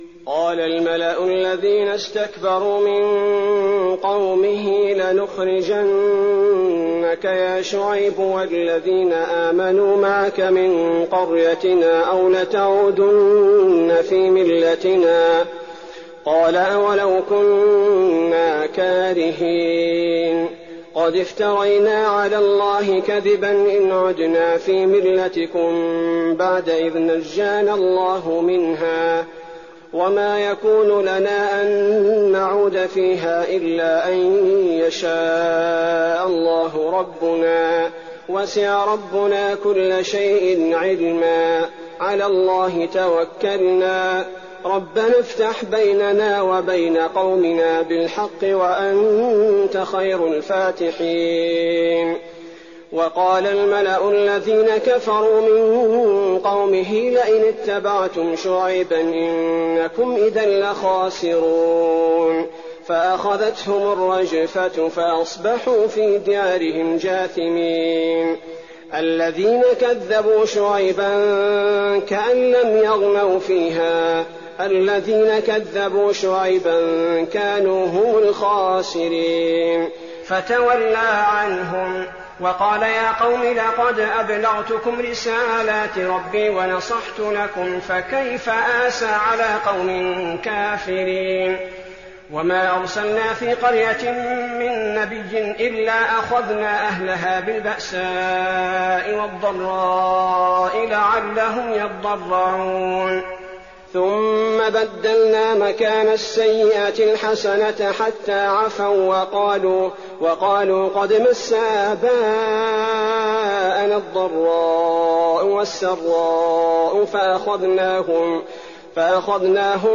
تراويح الليلة التاسعة رمضان 1419هـ من سورة الأعراف (88-171) Taraweeh 9th night Ramadan 1419H from Surah Al-A’raf > تراويح الحرم النبوي عام 1419 🕌 > التراويح - تلاوات الحرمين